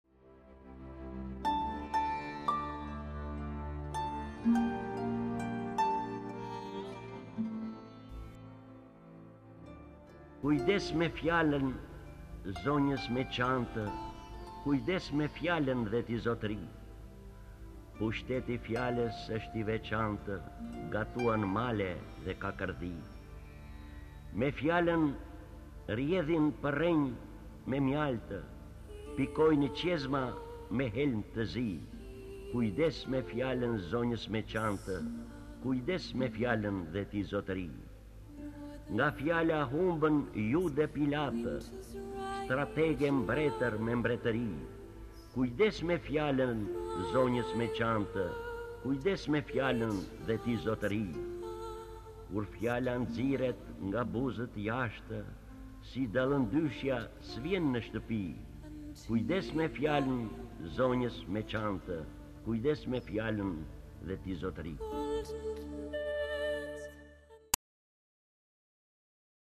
Lexuar nga D. Agolli KTHEHU...